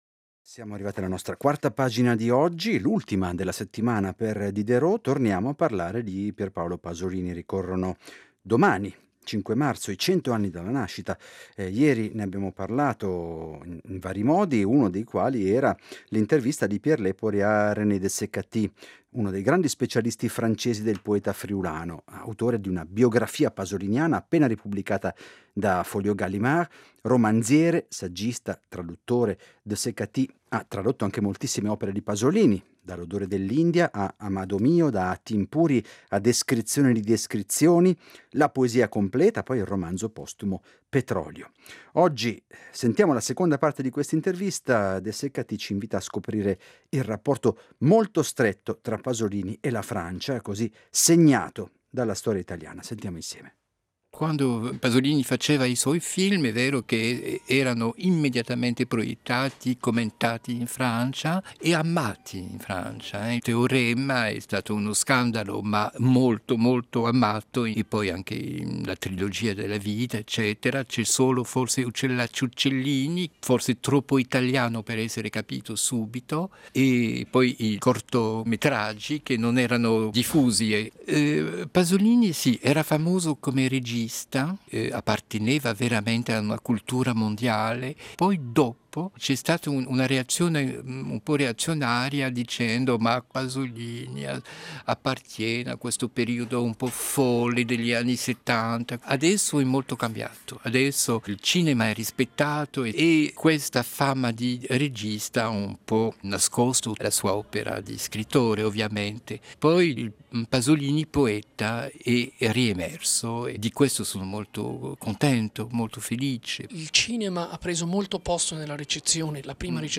la seconda parte dell’intervista